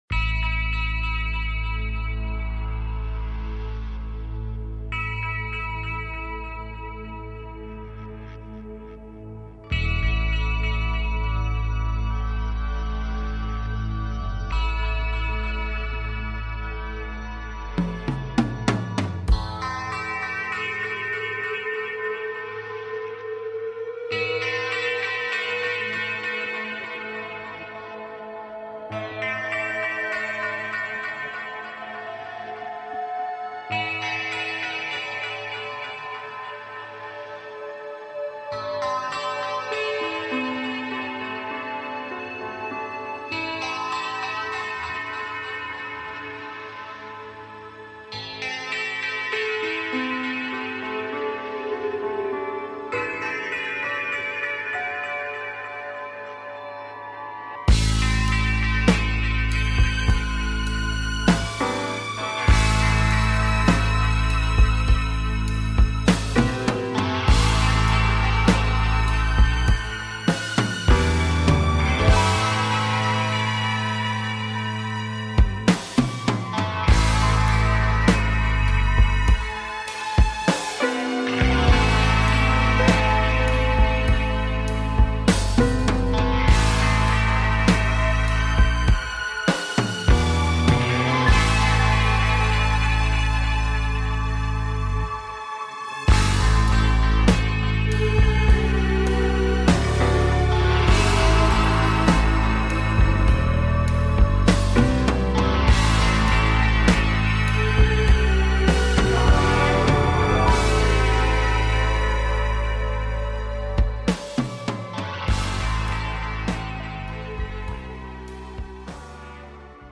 Show on Homesteading and taking caller questions